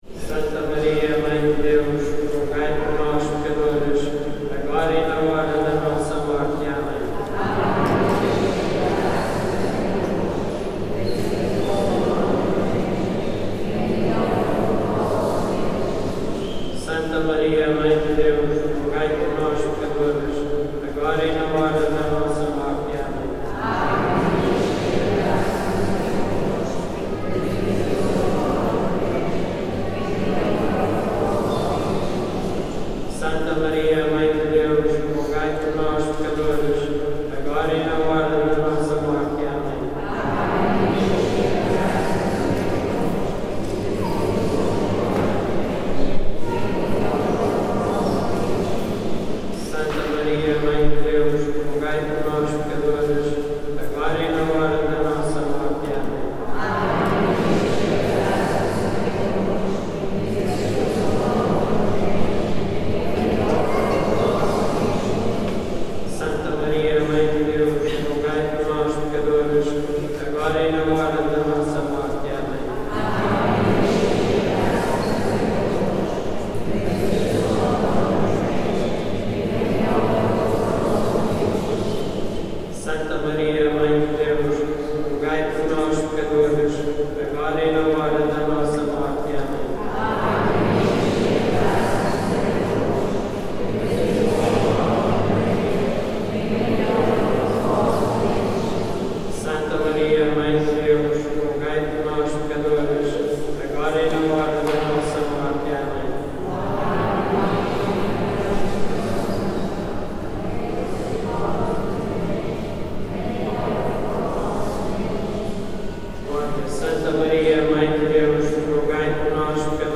Step into the serene atmosphere of the Santuario de Santa Luzia in Viana do Castelo, Portugal, as a Sunday church service unfolds. This recording captures the intimate moments of devotion and worship, as the priest leads the congregation in prayer. The gentle murmur of the congregation's responses creates a sense of community and reverence. As the service reaches its close, the congregation rises to their feet, and the majestic sound of the organ fills the sanctuary, accompanied by joyful singing of the worshippers.